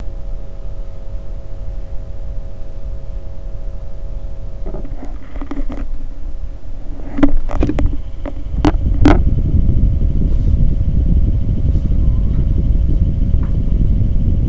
Une fois a l'horizontale, etiquette vers le haut, le ventillateur fait beaucoup plus de bruit qu'a la vertical, de plus c'est un bruit irrégulier et assez désagréble.
J'ai un petit echantillon son : D'abord a la verticale, puis a l'horizontal...
globefan.wav